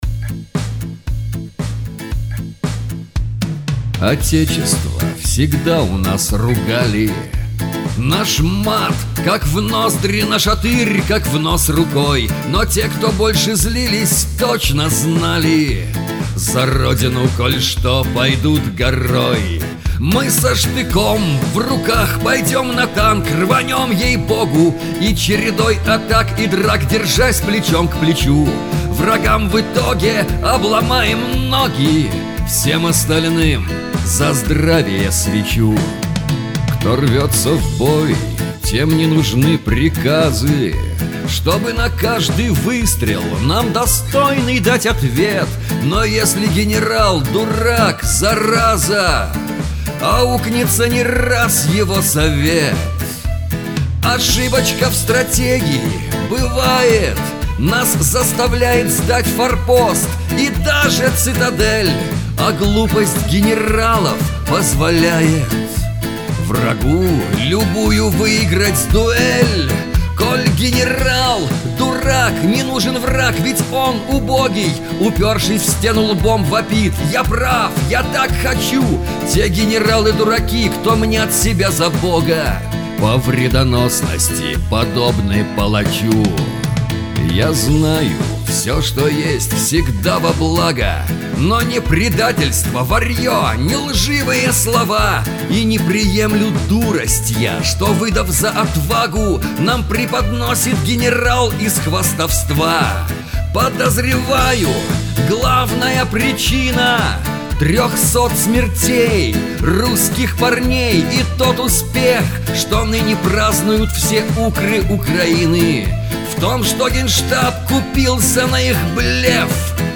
БАЛЛАДА ПРО ГЕНЕРАЛОВ-ДУРАКОВ
На мотив песни В. Высоцкого «В младенчестве нас матери пугали».